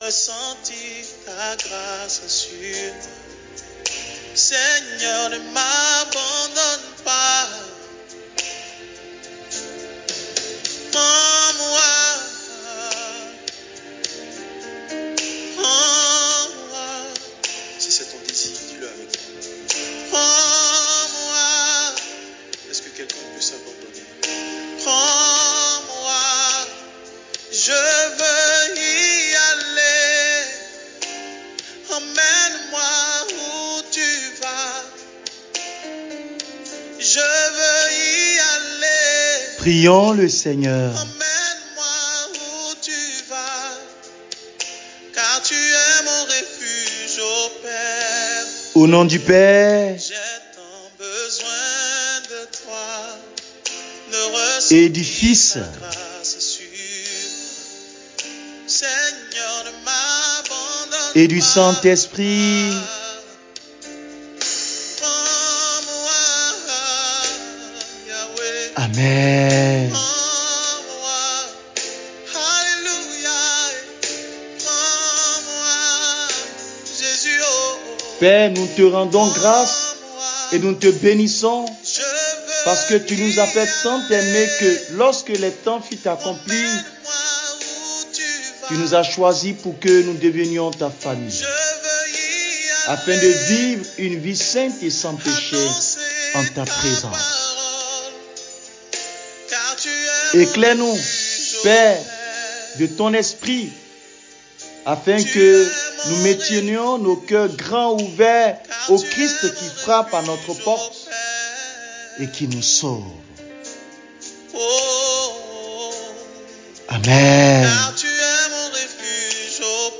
Enseignement: LE SECRET COMME LIEU DE RENCONTRE AVEC DIEU-1.mp3